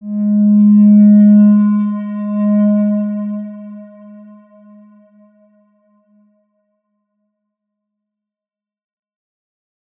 X_Windwistle-G#2-ff.wav